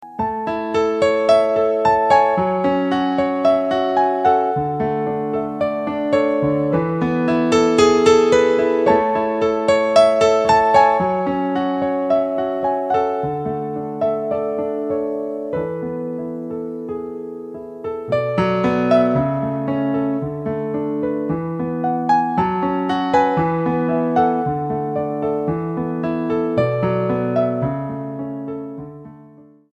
STYLE: Ambient/Meditational
Although instrumental music seldom appeals to me
create a wonderful atmosphere of peaceful tranquillity